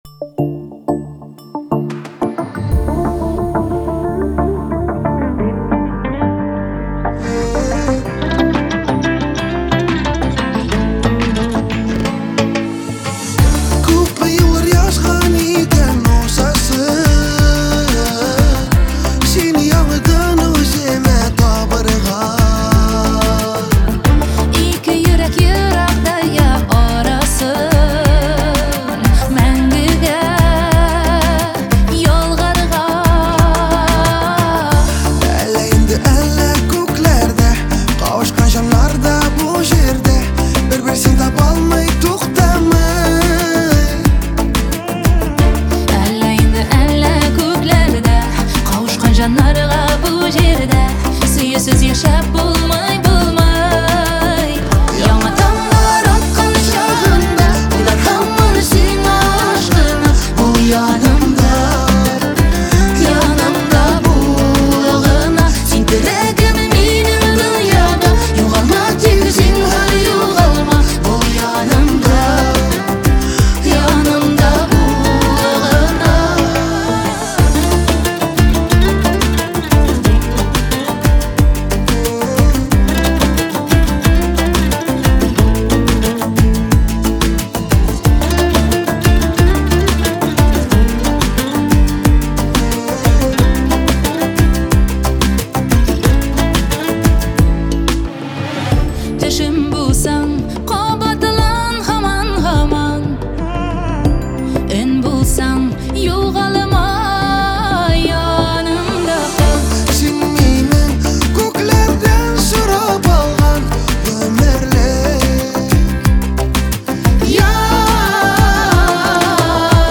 Узбекские песни Слушали